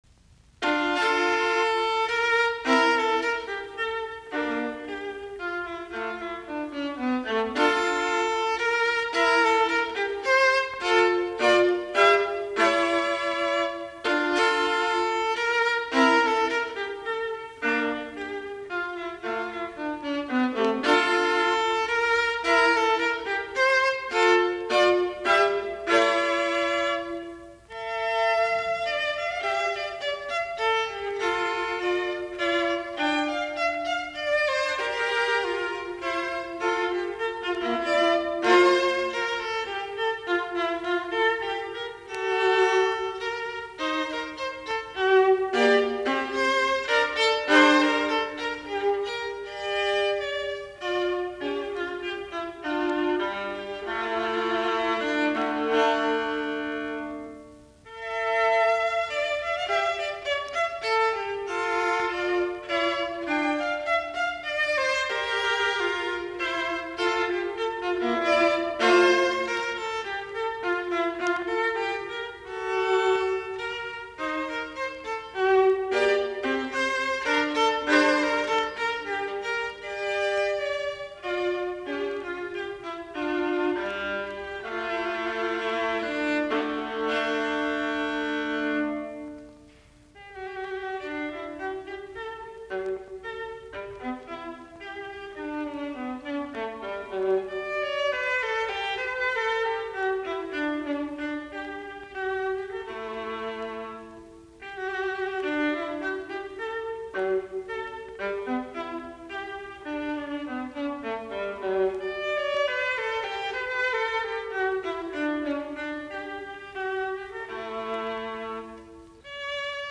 Soitinnus: Alttoviulu.